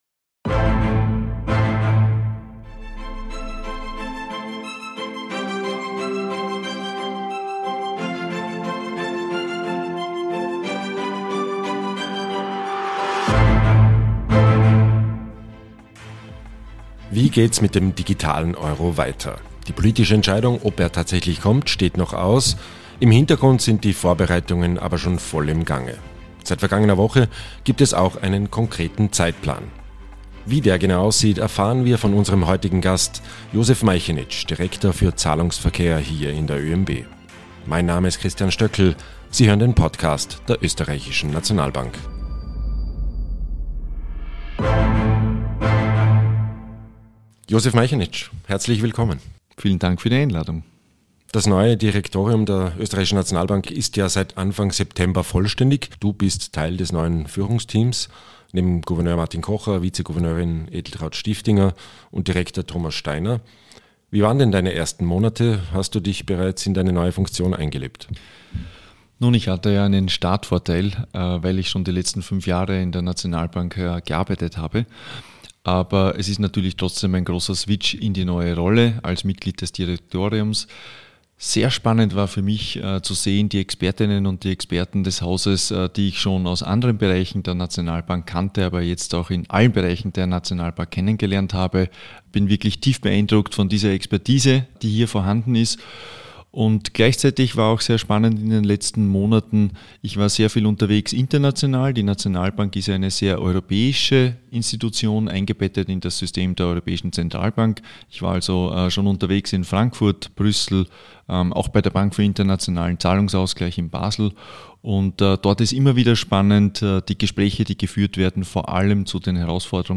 Seit kurzem gibt es auch einen konkreten Zeitplan für das Projekt. Wie dieser genau aussieht und warum Europa einen digitalen Euro dringend braucht, erläutert OeNB-Direktor Josef Meichenitsch in der aktuellen Folge des Nationalbank-Podcasts.